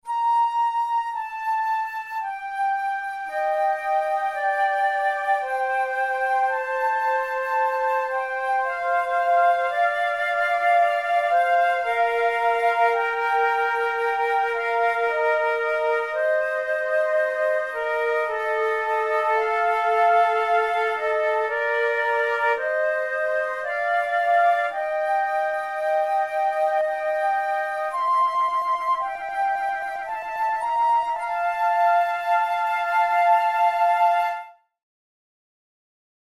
This very short Adagio is the third movement of a sonata in B-flat major for two flutes by the German Baroque composer and music theorist Johann Mattheson.
Categories: Baroque Sonatas Written for Flute Difficulty: easy